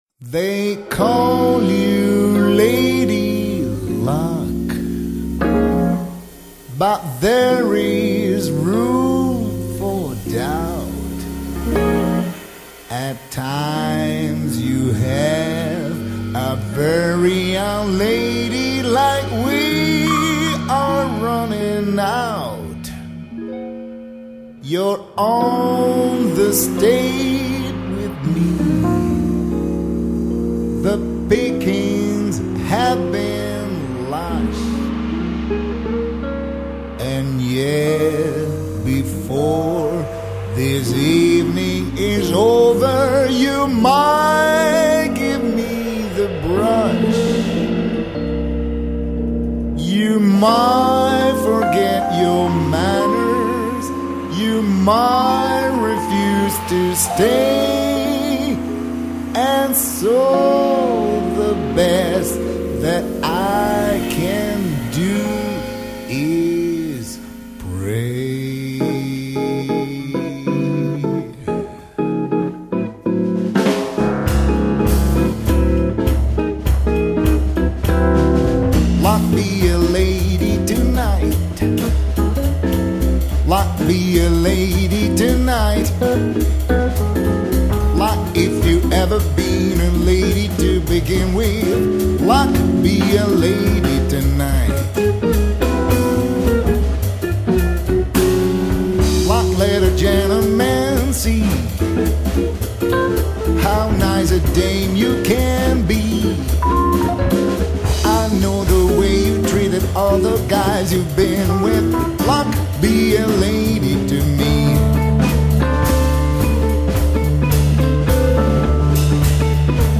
类别： 爵士
艺人：多人演出
主奏乐器：钢琴
以钢琴、鼓、贝斯、吉他的四重奏组合